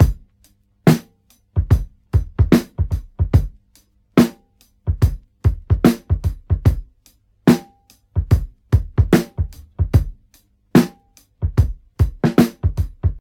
73 Bpm Drum Loop G# Key.wav
Free drum groove - kick tuned to the G# note. Loudest frequency: 400Hz
73-bpm-drum-loop-g-sharp-key-HvV.ogg